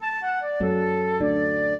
flute-harp
minuet0-8.wav